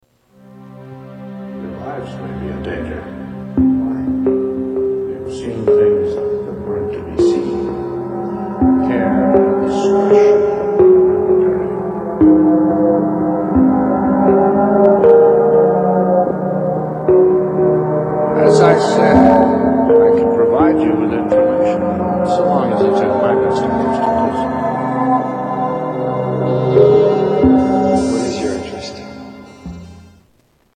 Television Music